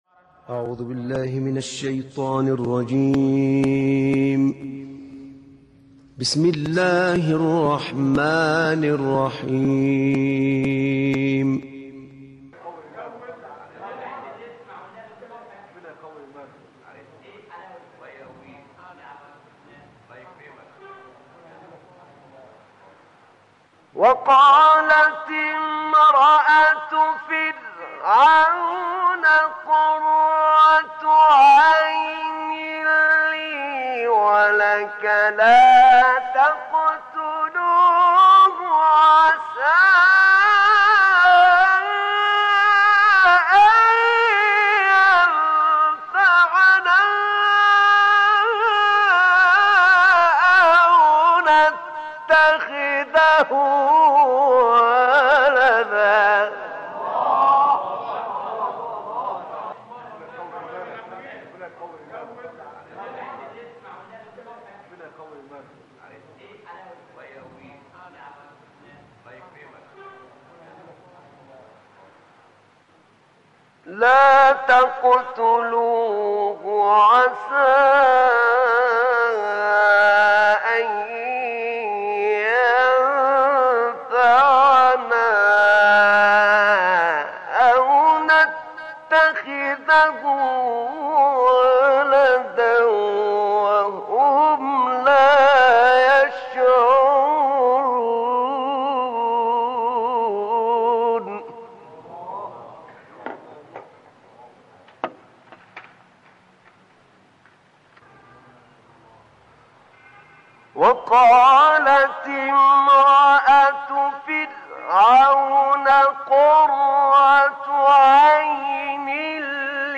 تلاوت آیات 9 تا 24 سوره قصص توسط استاد احمد نعینع
تلاوت قرآن کريم
قاری مصری